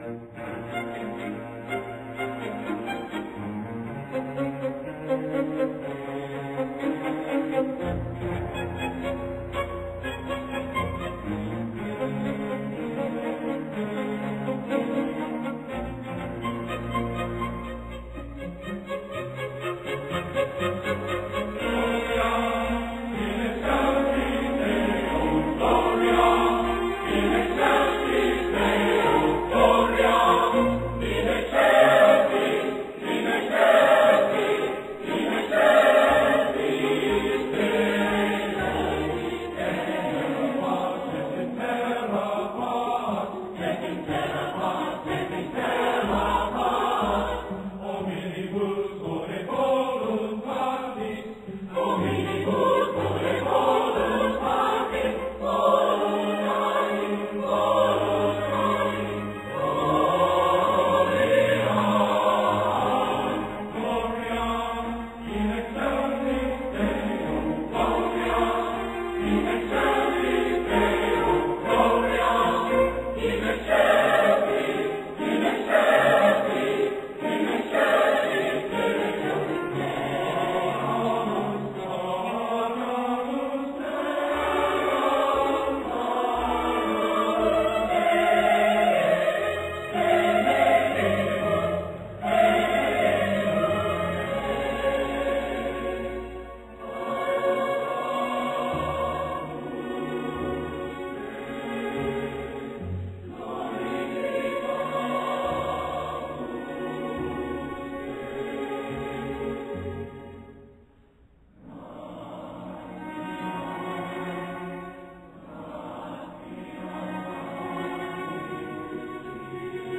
Voicing: SAB or SATB